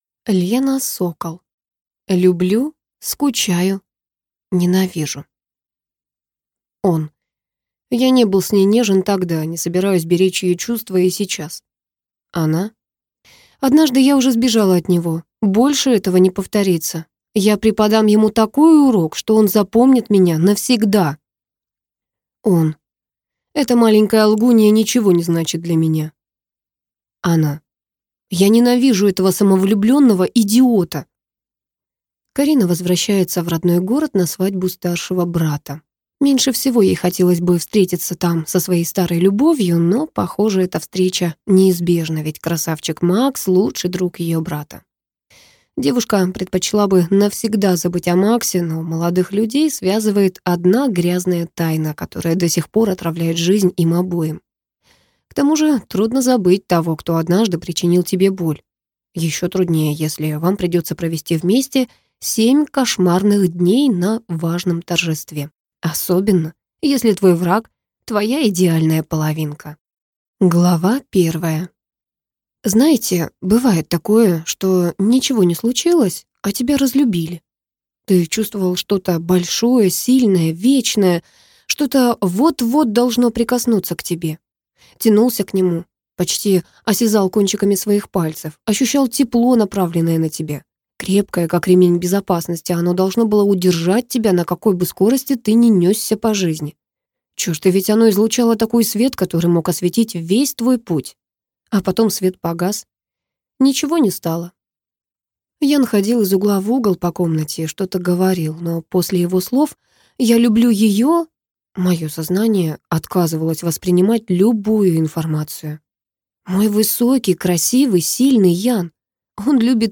Аудиокнига Люблю, скучаю… Ненавижу!